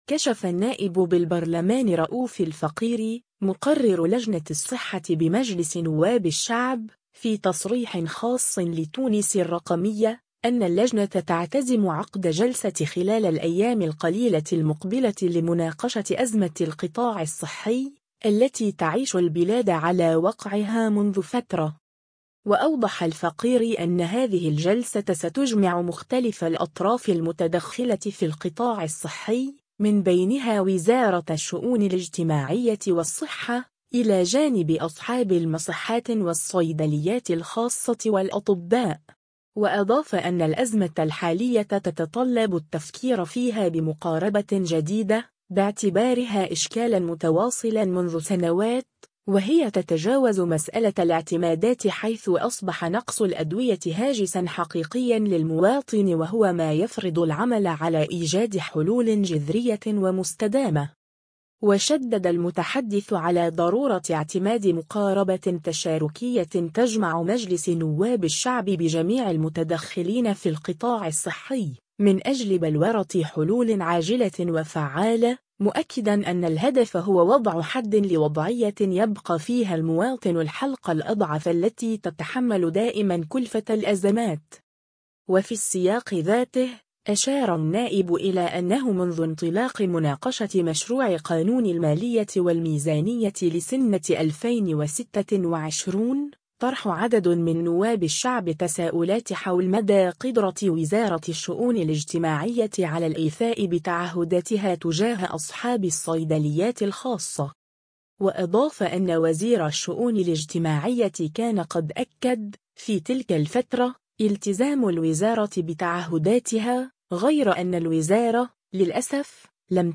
كشف النائب بالبرلمان رؤوف الفقيري، مقرر لجنة الصحة بمجلس نواب الشعب، في تصريح خاص لـ”تونس الرقمية”، أن اللجنة تعتزم عقد جلسة خلال الأيام القليلة المقبلة لمناقشة أزمة القطاع الصحي، التي تعيش البلاد على وقعها منذ فترة.